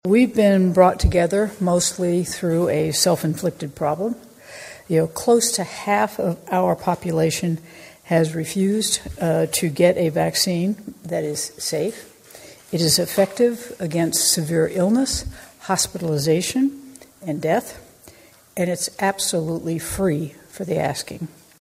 During a news conference Tuesday, Governor Kelly said this is a step that could have been avoided.